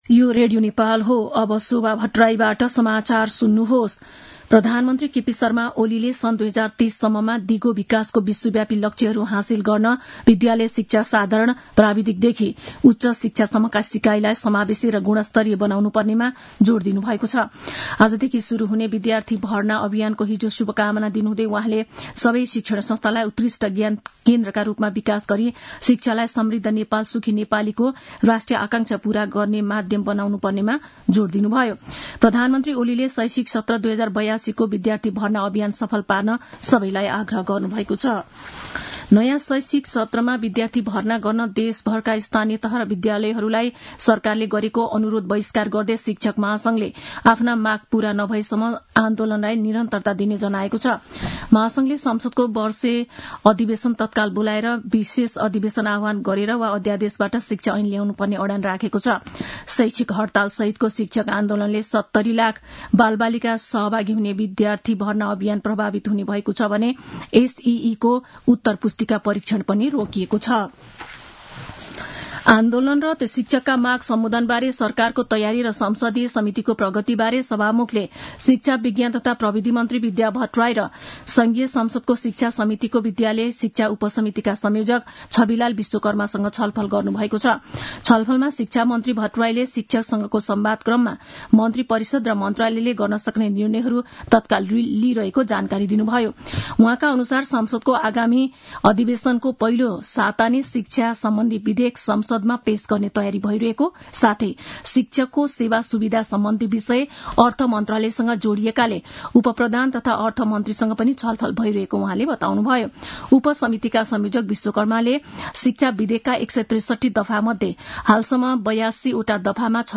मध्यान्ह १२ बजेको नेपाली समाचार : २ वैशाख , २०८२